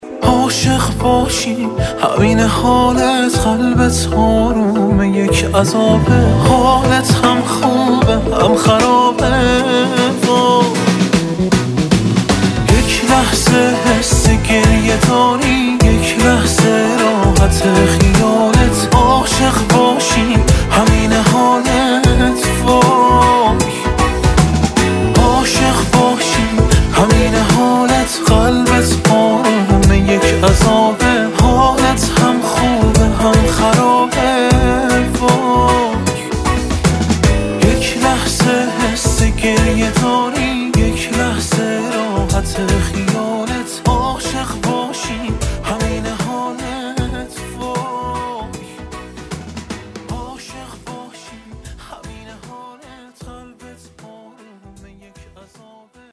رینگتون احساسی-شاد و با کلام